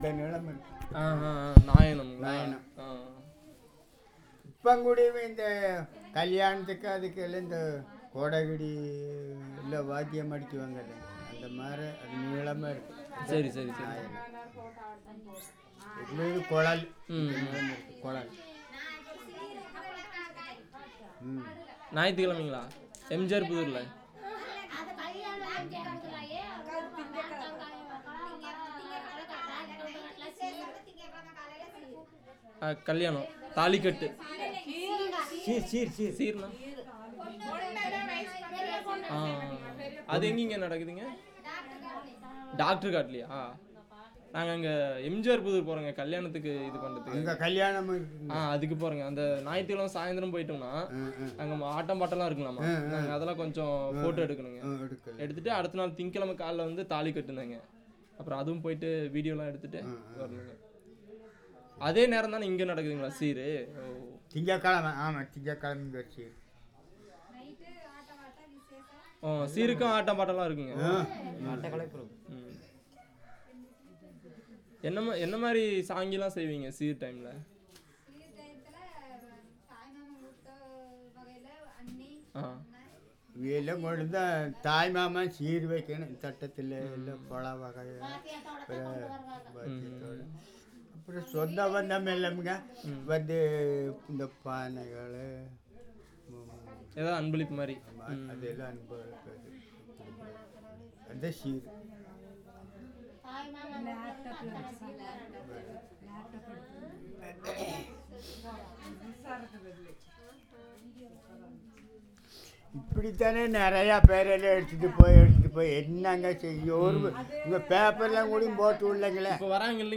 Conversation about the purpose of documentation